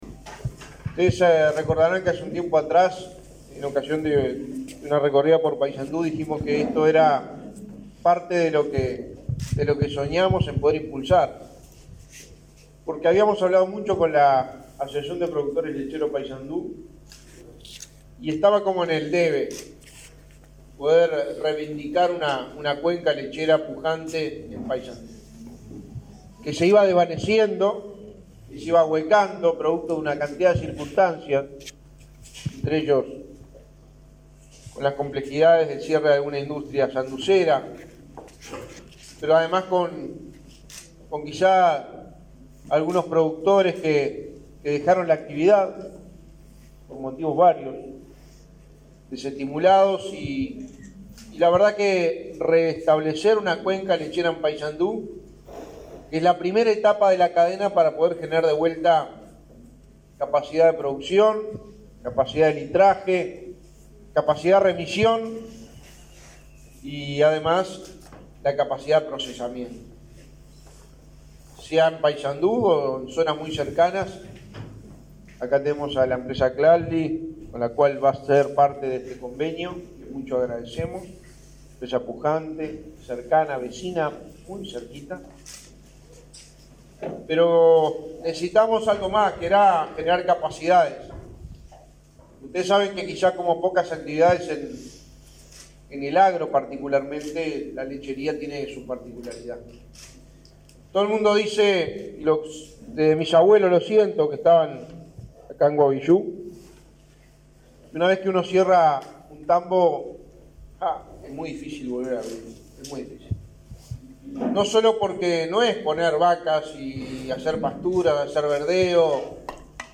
Palabras de Álvaro Delgado durante firma de convenio sobre lechería
El secretario de la Presidencia, Álvaro Delgado, de visita en Paysandú, participó este miércoles 30 de la firma del convenio para la instalación de